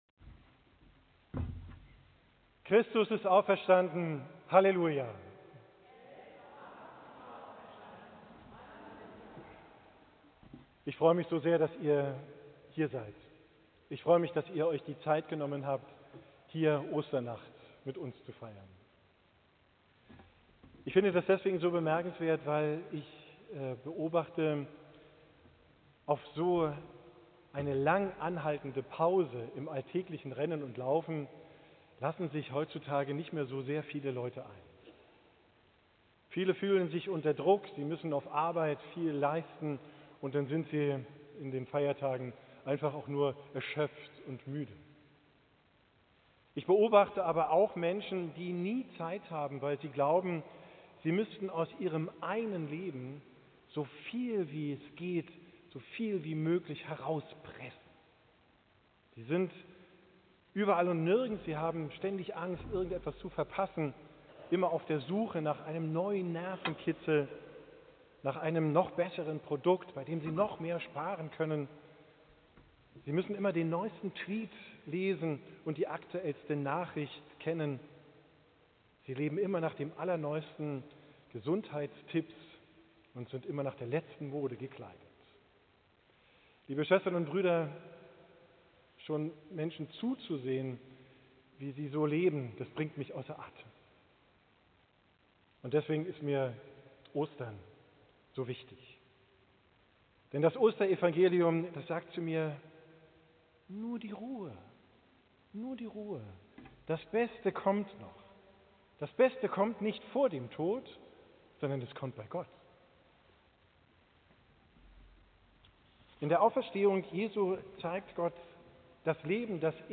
Lesung Matthäus 28: 1 Als aber der Sabbat vorüber war und der erste Tag der Woche anbrach, kamen Maria Magdalena und die andere Maria, um nach dem Grab zu sehen. 2 Und siehe, es geschah ein großes Erdbeben.